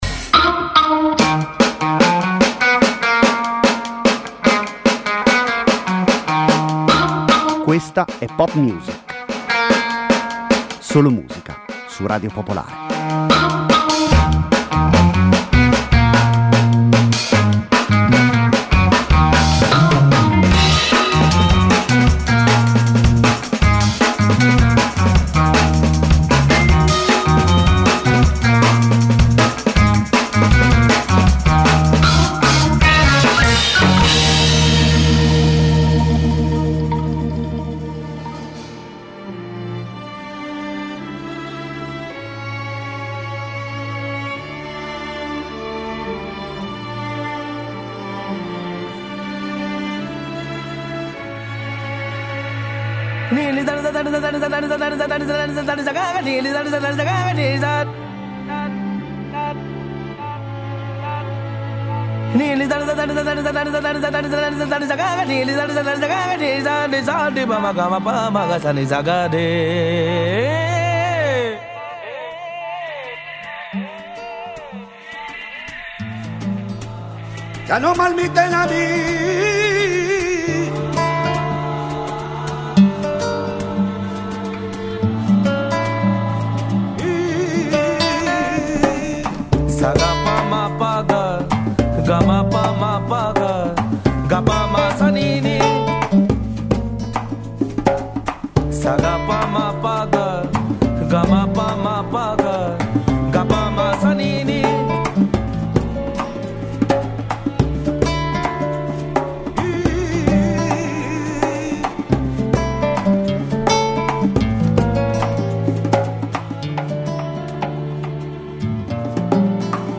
Senza conduttori, senza didascalie: solo e soltanto musica.